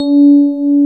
Index of /90_sSampleCDs/Roland L-CD701/KEY_Pop Pianos 4/KEY_Pop Pno + EP